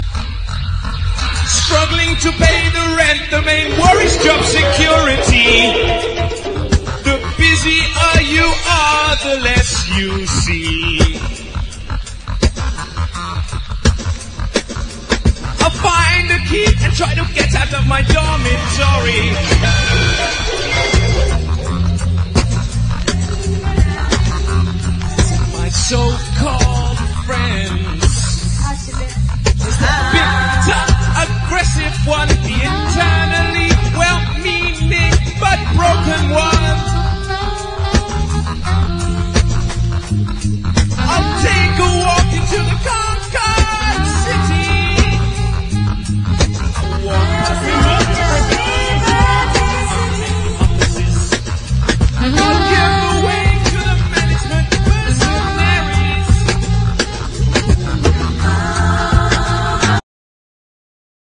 ROCK / 80'S/NEW WAVE. / POST PUNK. / DUB